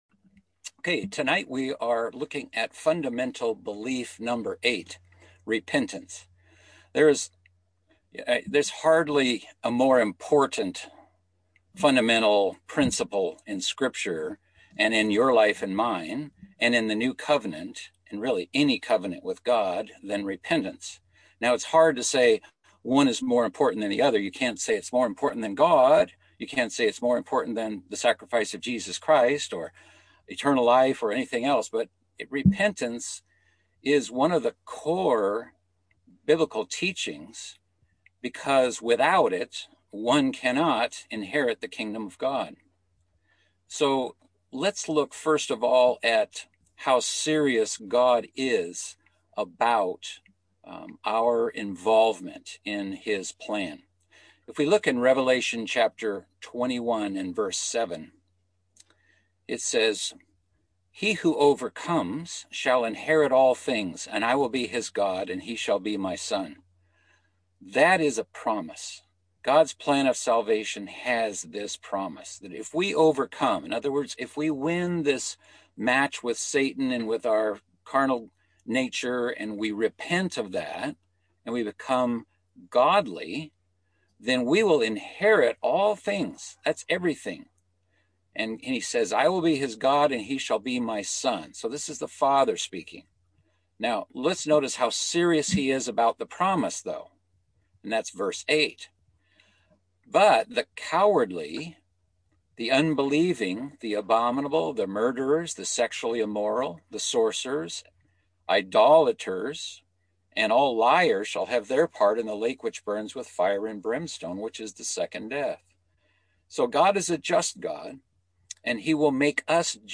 Fundamental Biblical Beliefs - Bible Study